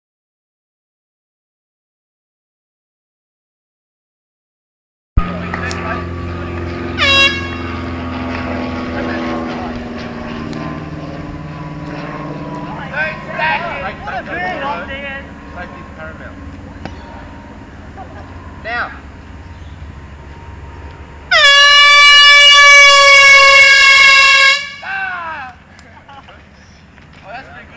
doppler_velo.mp3